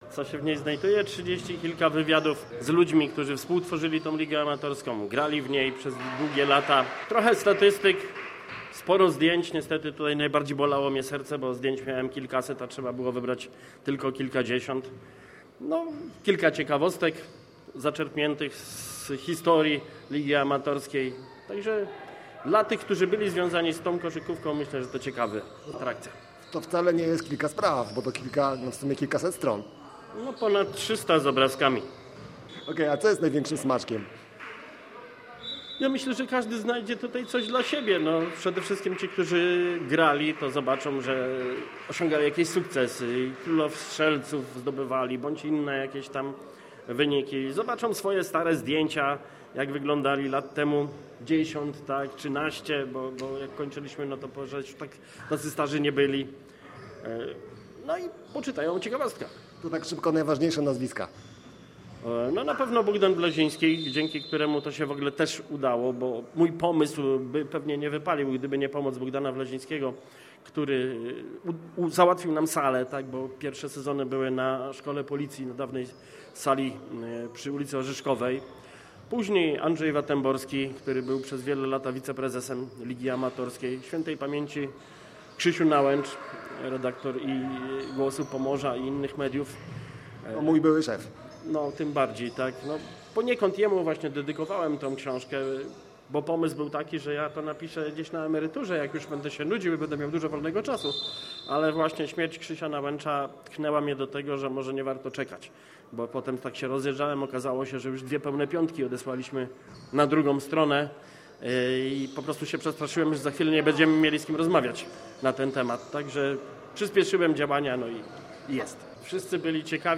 Z okazji 30-lecia istnienia Słupskiej Amatorskiej Ligi Koszykówki w sobotę w Hali Gryfia odbył się wyjątkowy mecz weteranów, który zgromadził byłych zawodników i sympatyków niezwykłej inicjatywy.